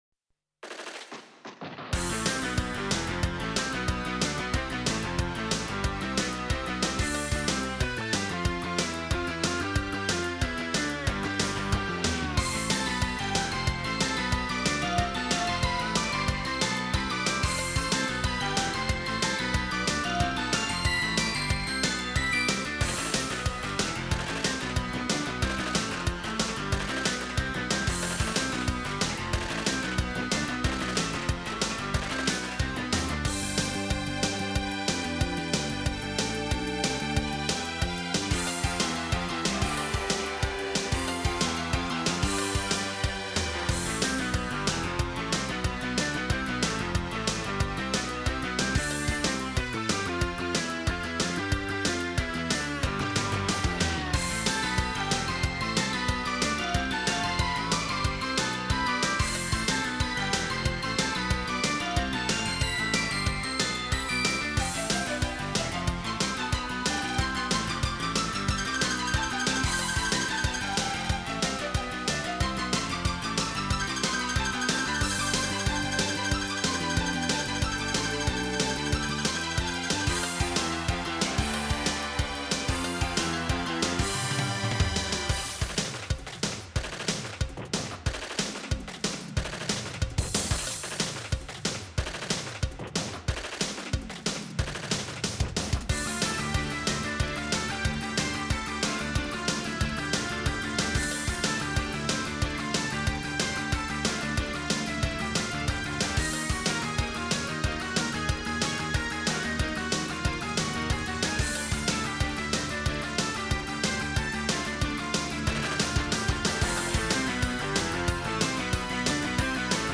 Very good to hear @ 1:43 or 3:09